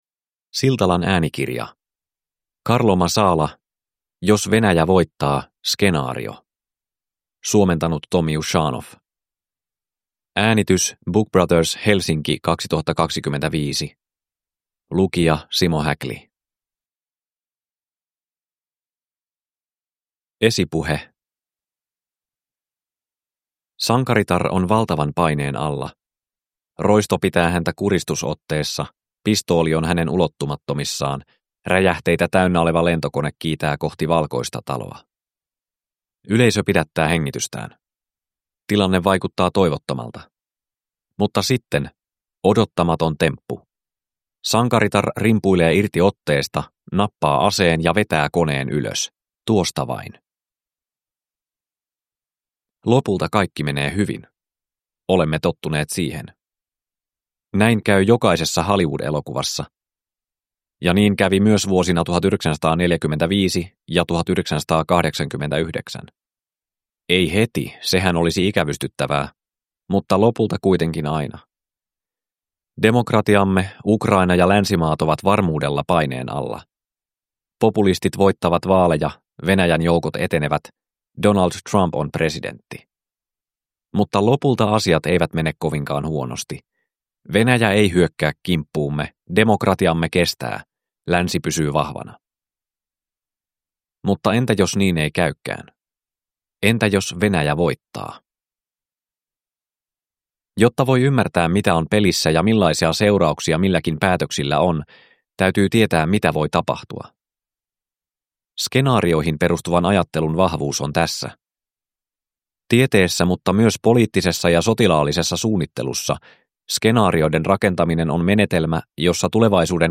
Jos Venäjä voittaa. Skenaario (ljudbok) av Carlo Masala